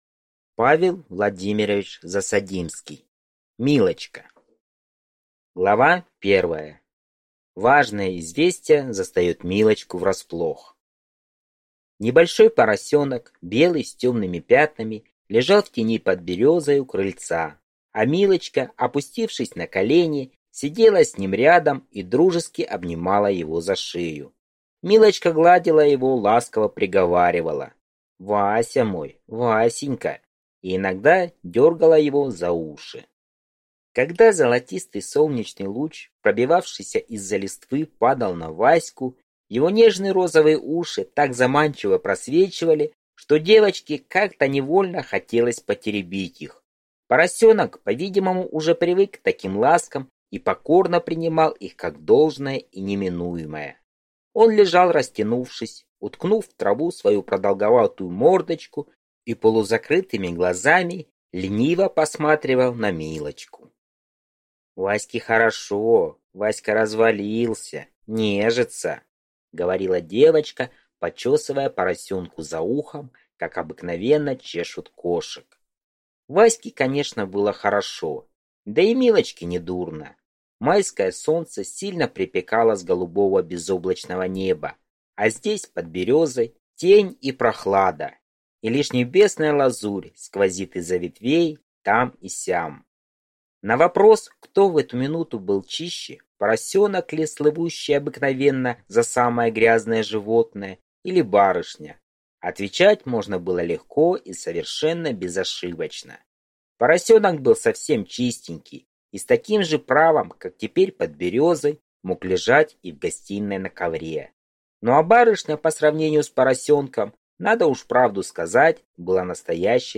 Аудиокнига Милочка | Библиотека аудиокниг
Прослушать и бесплатно скачать фрагмент аудиокниги